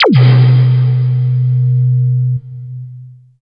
70 BLIP   -L.wav